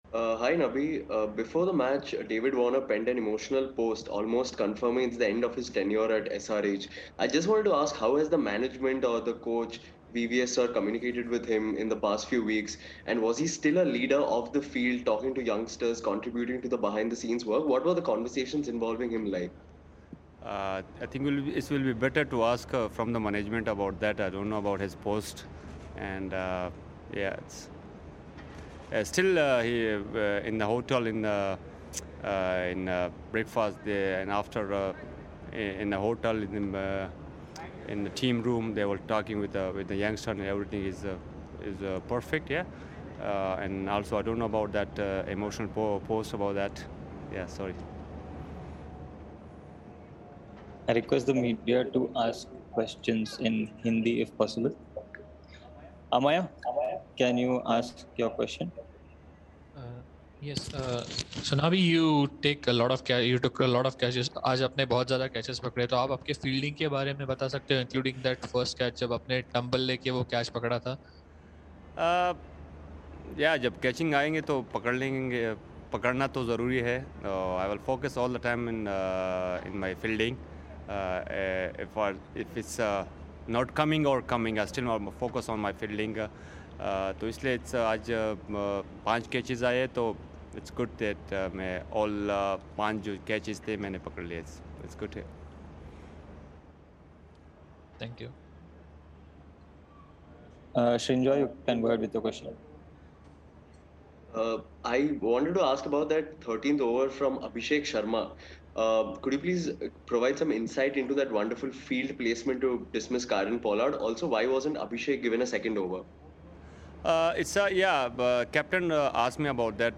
Mohammad Nabi of Sunrisers Hyderabad addressed the media after the game